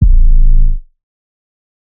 Sleepy 808.wav